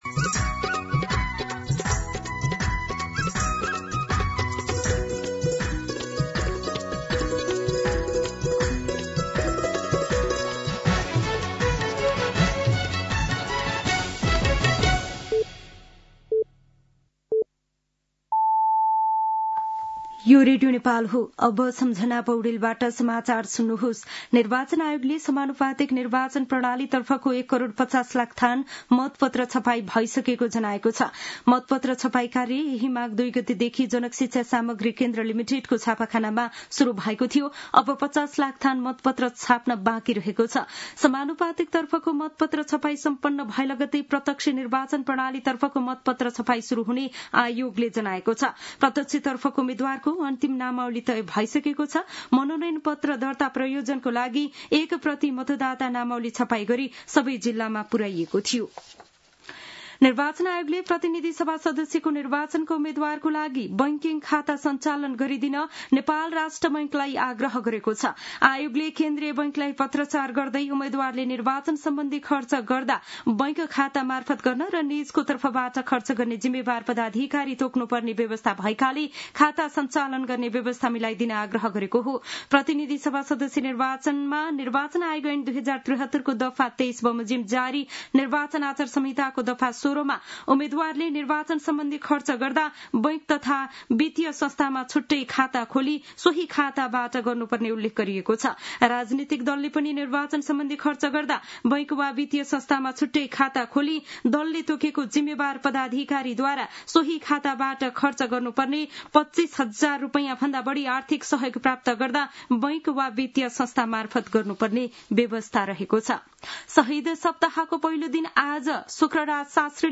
दिउँसो ४ बजेको नेपाली समाचार : १० माघ , २०८२
4-pm-News-10-10.mp3